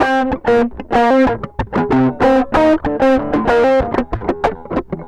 Track 13 - Distorted Guitar Wah 02.wav